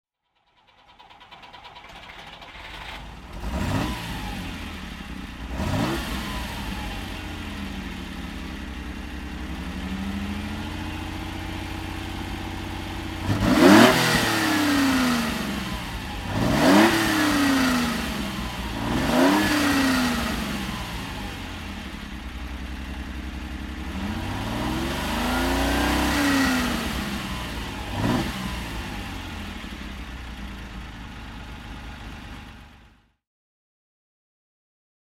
Lancia Stratos HF Stradale (1975) - Starten und Leerlauf
Lancia_Stratos_1975.mp3